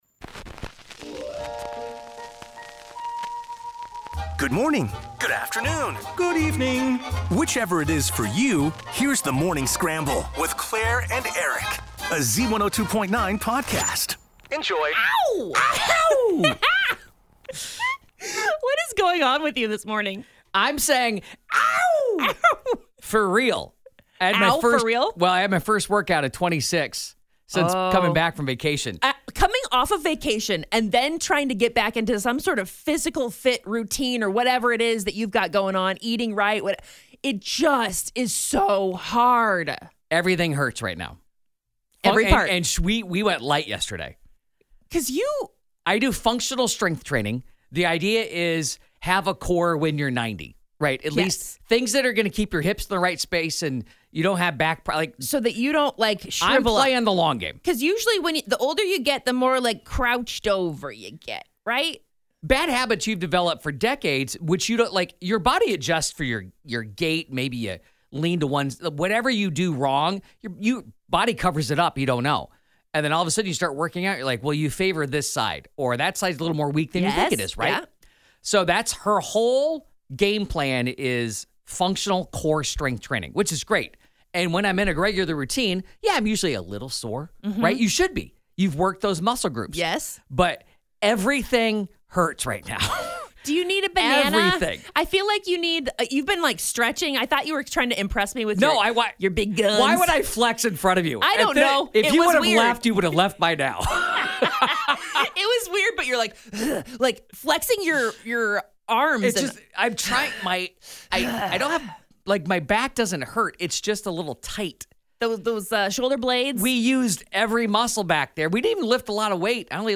Coach Jan Jensen joins The Morning Scramble!